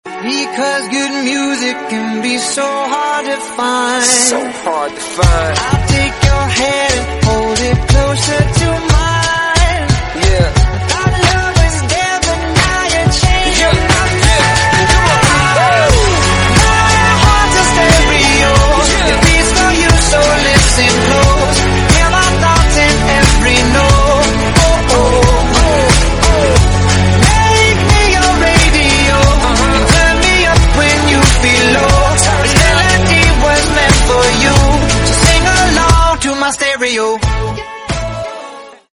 Ringtones Category: Top Ringtones